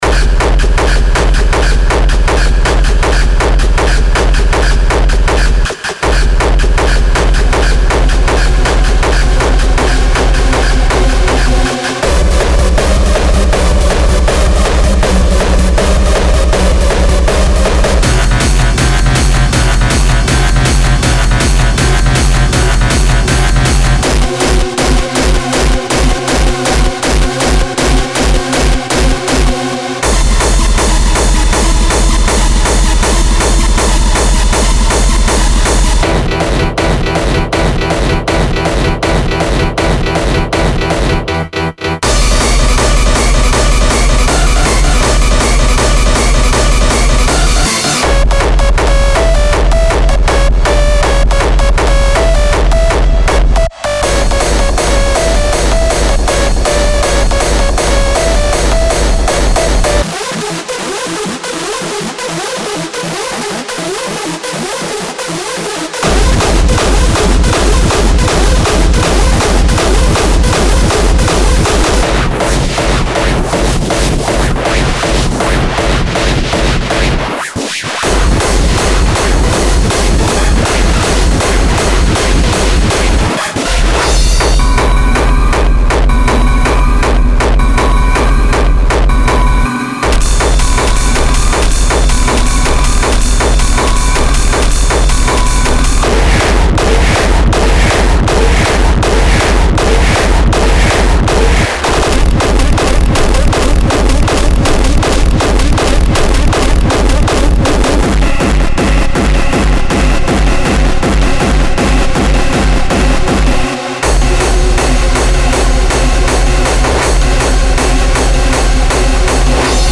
Genre:Hard Dance
必要な主要要素に焦点を当て、膨大な数のキックとシンセを収録しました。
豊富なSFX、ドラムショット、そしていくつかのパーカッションループもあり、自由にアレンジ可能です。
27 Drum Loops
85 Synth Loops